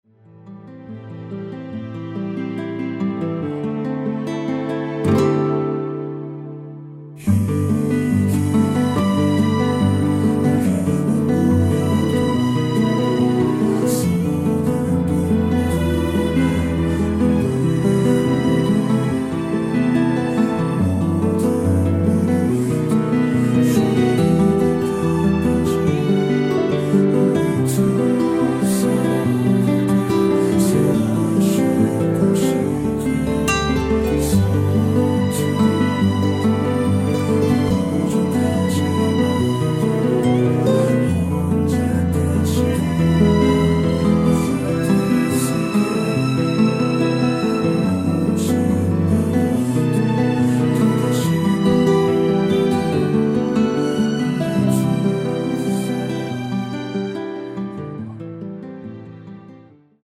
원키에서 (-2)내린 코러스 포함된MR 입니다.